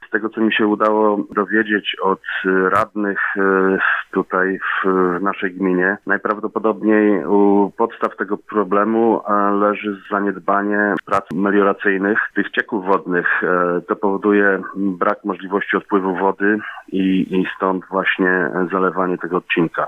– mówi jeden z mieszkańców gminy.